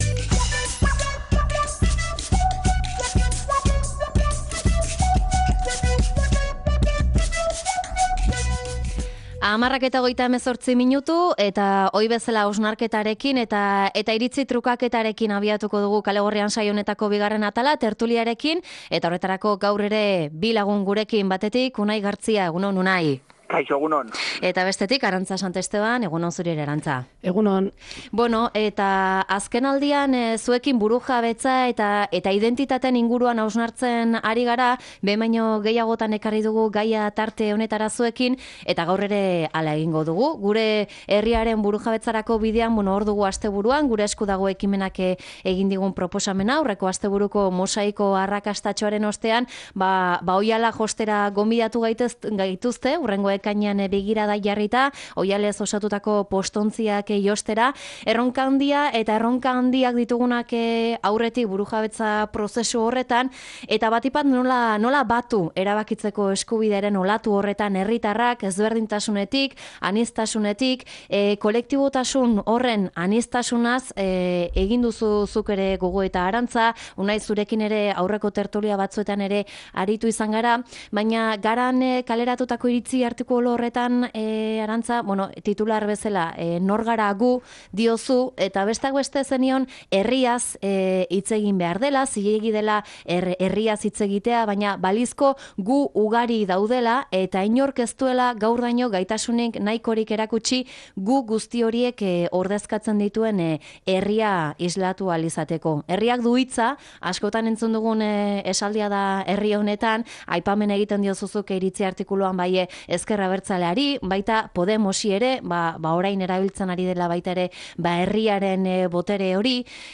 Tertulia: zer da Herria? nola eta norekin eraikitzen da Herria?